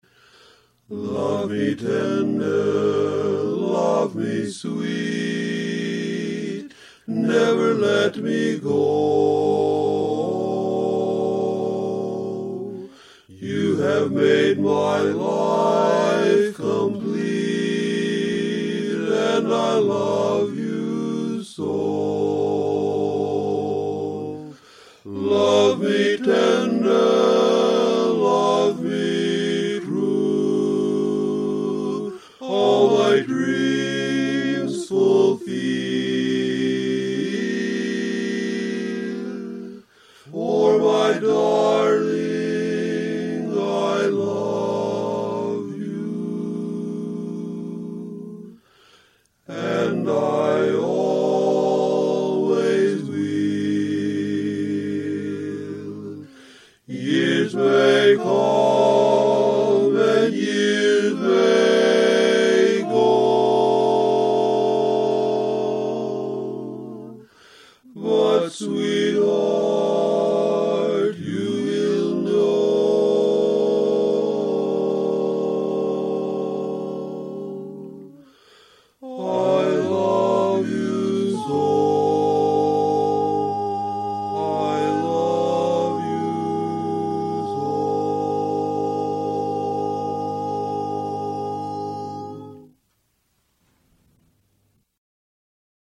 Index of /MP3LearningTracks/SingingValentines/Bari
LoveMeTender-Bari-ChorusOnly.mp3